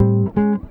Gtr_03.wav